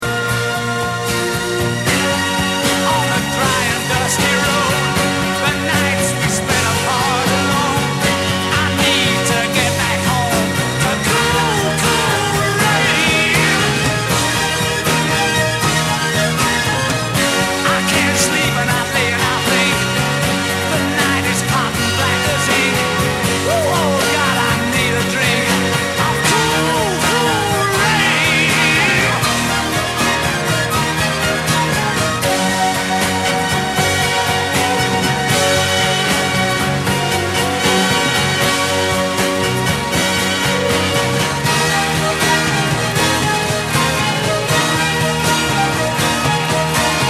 My Favorite Songs with Notable Bass Guitar Performances